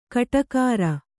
♪ kaṭakāra